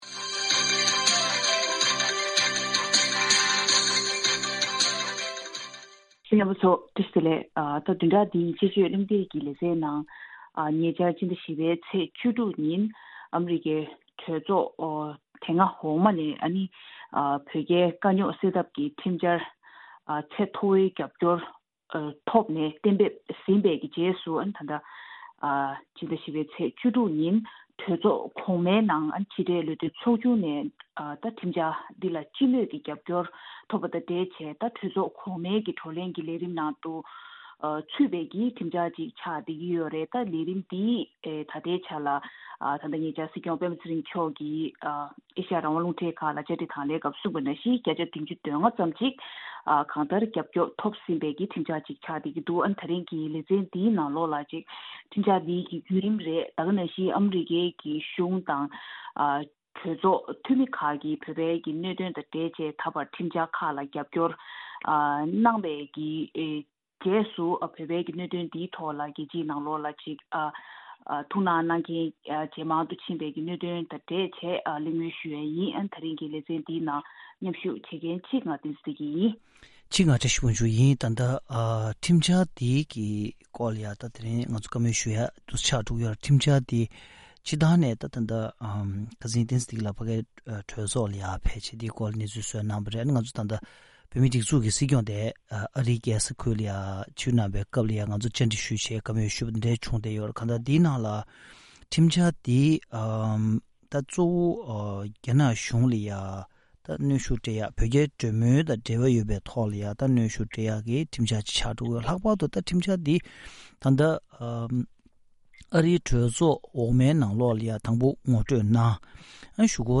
དཔྱད་གཞིའི་གླེང་མོལ